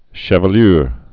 (shəv-lür)